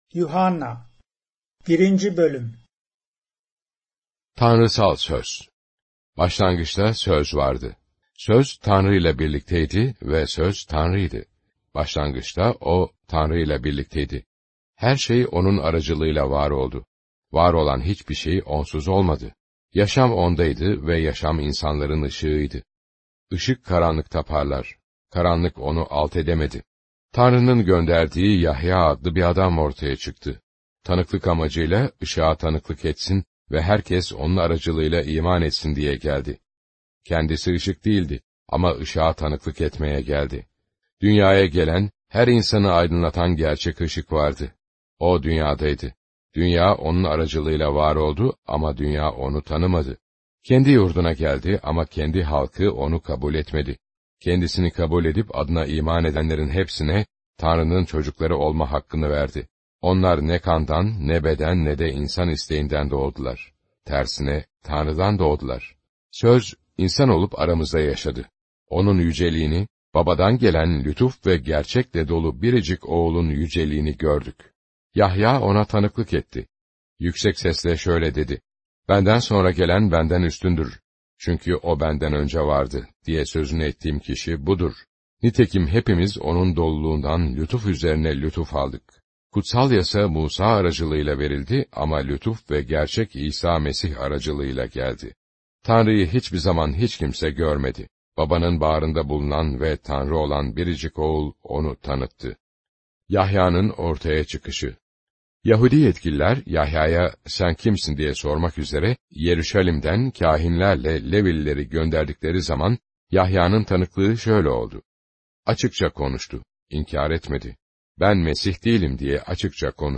• Word for word narration
• Voice only Bible reading
turkish-bible-7417-john-1.mp3